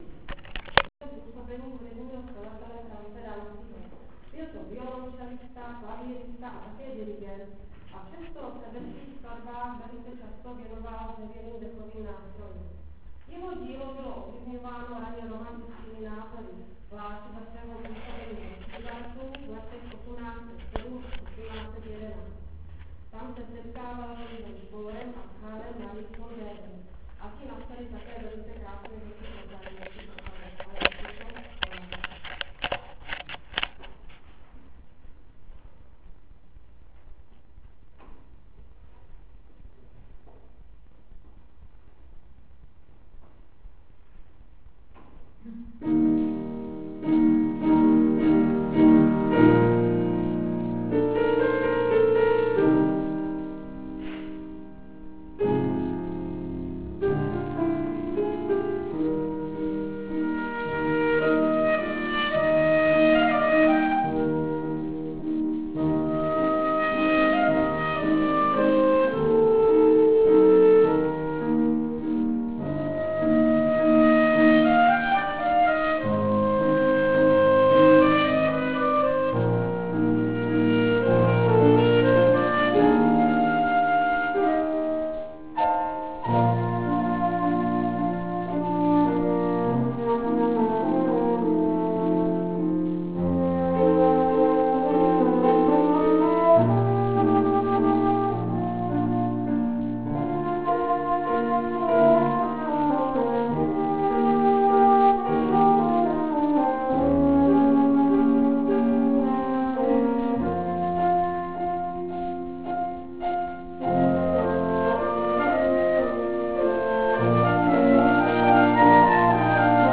Dechov� trio TIBIA (nahr�vky z koncertu - 26. X.)
Amat�rsk� proveden� - form�t WAV, 16, 10 a 4,5 minut v�etn� �vodn�ho slova (slab�ji sly�iteln�) W.A.Mozart - Divertimento �. 1 F.Danzi - Concertino op. 47 J.Zimmer - Tatry op. 11 Podrobn� program koncertu